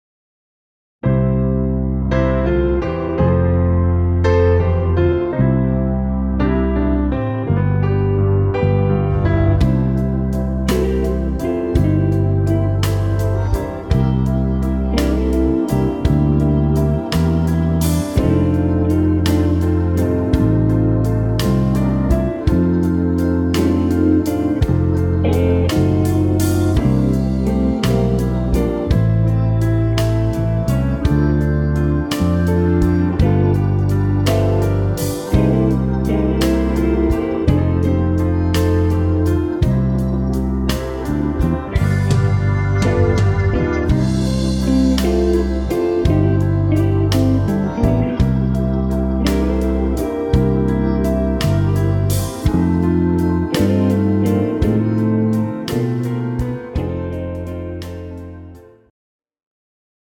country blues style
tempo 56 bpm
key C/Bb male/female singer
Male/Female singers backing track
Our backing track is in slow tempo country blues style